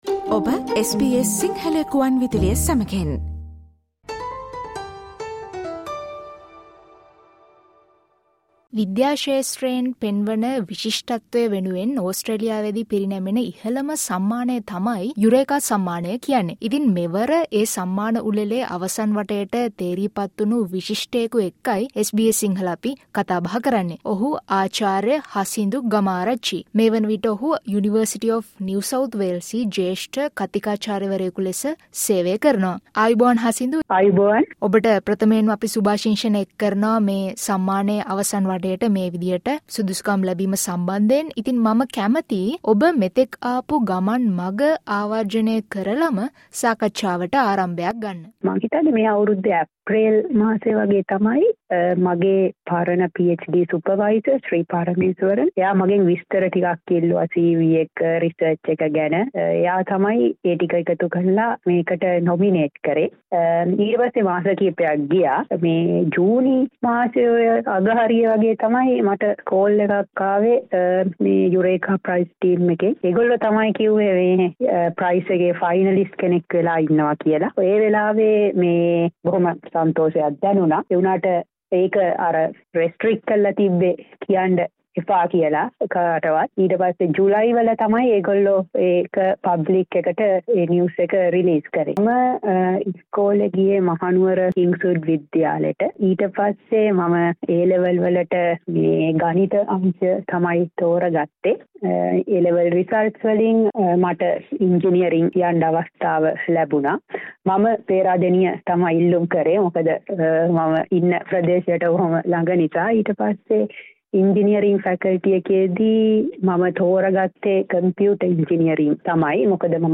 ඔහුගේ පර්යේෂණය පිළිබඳව සහ අනාගත පර්යේෂකයන්ට ඔහුගේ පණිවිඩය රැගත් SBS සිංහල සේවය ගෙන එන සාකච්ඡාවට සවන් දෙන්න.